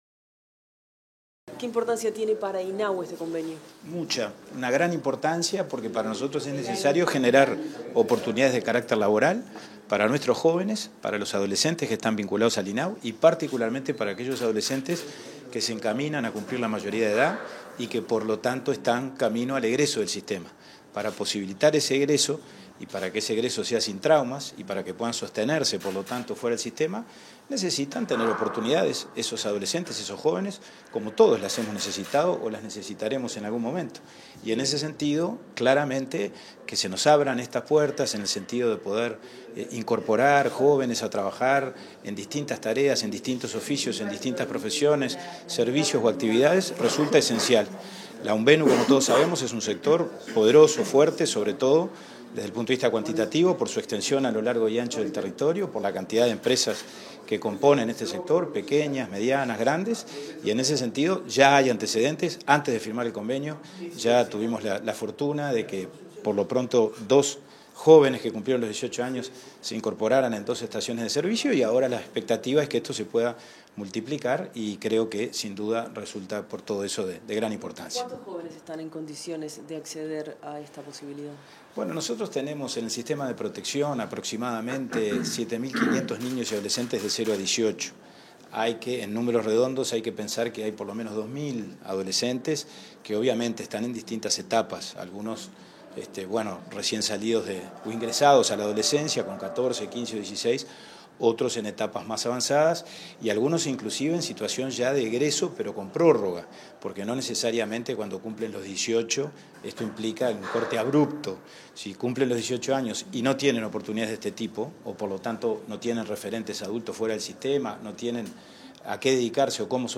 Declaraciones a la prensa del presidente de INAU, Pablo Abdala
Declaraciones a la prensa del presidente de INAU, Pablo Abdala 02/08/2022 Compartir Facebook X Copiar enlace WhatsApp LinkedIn El Instituto del Niño y el Adolescente de Uruguay (INAU) firmó un convenio con la Unión de Vendedores de Nafta, este 2 de agosto, para concretar primeras experiencias laborales de jóvenes vinculados con servicios de la dependencia pública. Tras el evento, Abdala efectuó declaraciones a la prensa.